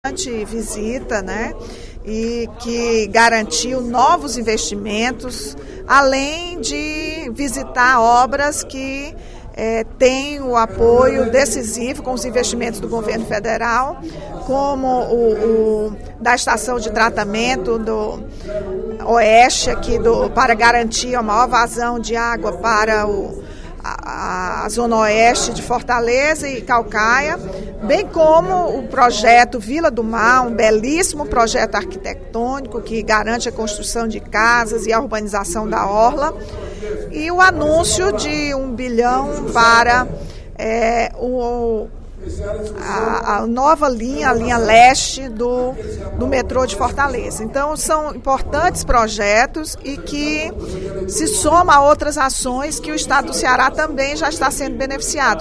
A deputada Rachel Marques (PT) destacou, durante pronunciamento na tribuna da Assembleia Legislativa nesta quarta-feira (29/02), o anúncio feito pela presidenta Dilma Rousseff de investimentos em várias obras no Estado.